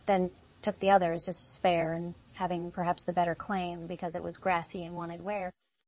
tortoise-tts - (QoL improvements for) a multi-voice TTS system trained with an emphasis on quality